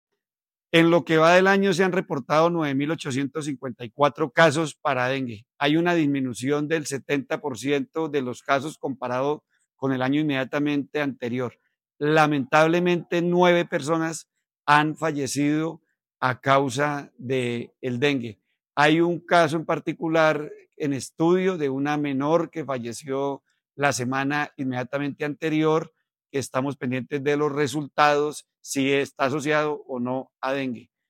Edwin Prada, Secretario de Salud de Santander